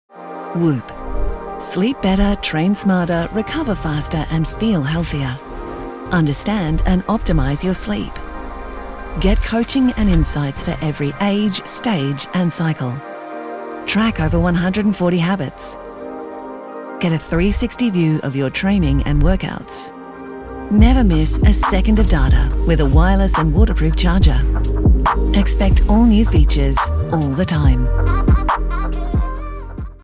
Female
In my natural Australian accent I strike a friendly, intelligent, warm and energetic tone suitable for an upbeat commercial sound or a more sophisticated timbre for e-learning.
Television Spots
National Australian Tv Campaign
All our voice actors have professional broadcast quality recording studios.